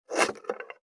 487野菜切る,咀嚼音,ナイフ,調理音,まな板の上,料理,
効果音厨房/台所/レストラン/kitchen食器食材